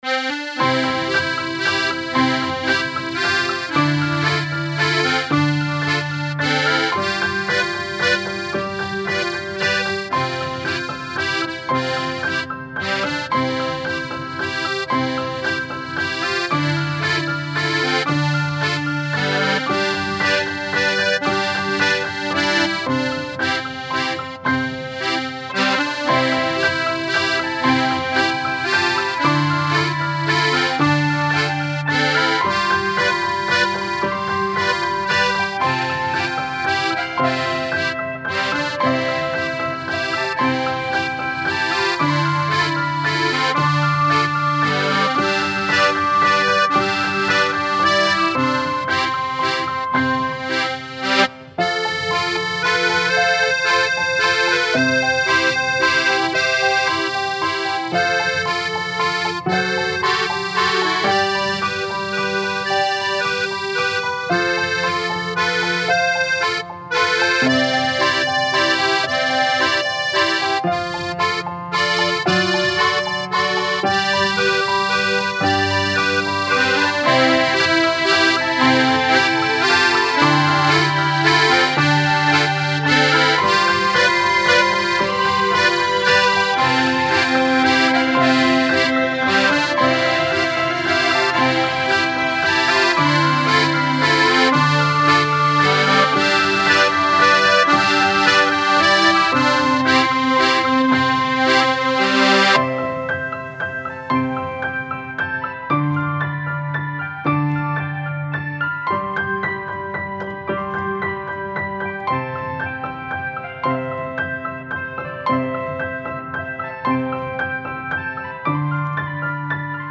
Style Style Classical, World
Mood Mood Bright, Calming
Featured Featured Accordion, Piano, Strings
BPM BPM 113
Peppy and catchy little tune.